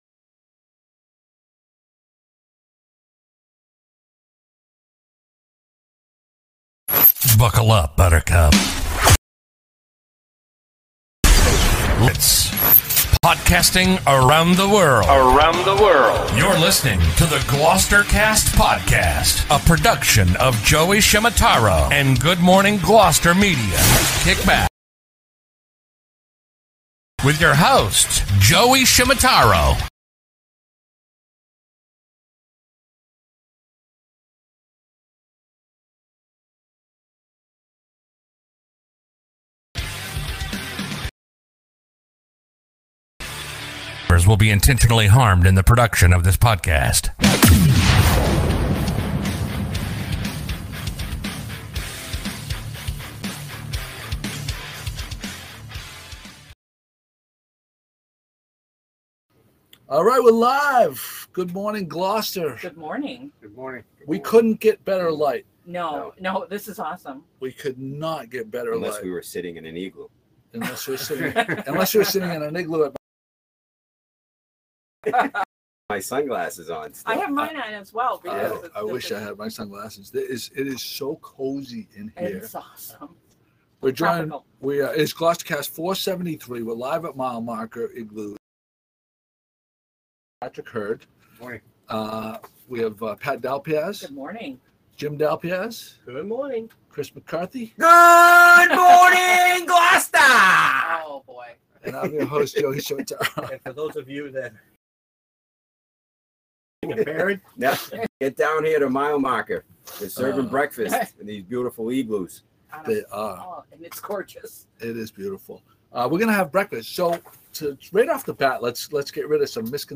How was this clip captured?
Live From The Mile Marker One Igloos